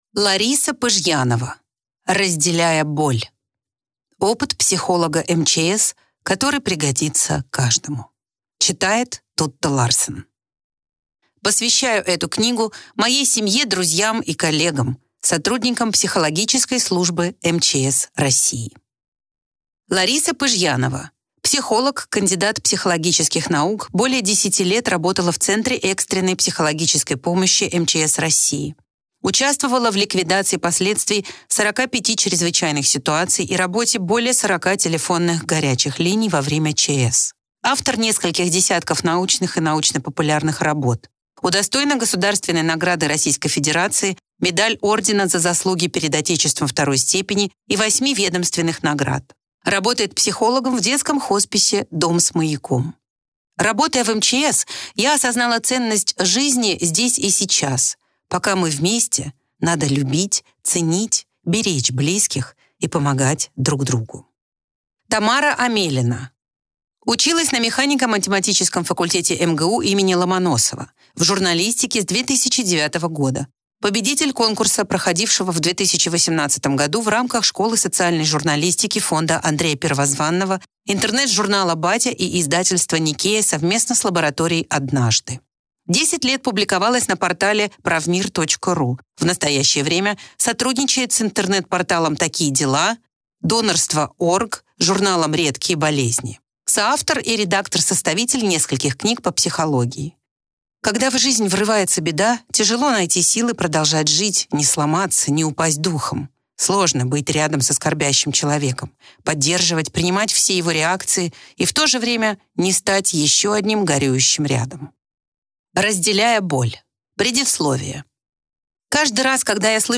Аудиокнига Разделяя боль. Опыт психолога МЧС, который пригодится каждому | Библиотека аудиокниг